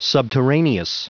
Prononciation du mot subterraneous en anglais (fichier audio)
Prononciation du mot : subterraneous
subterraneous.wav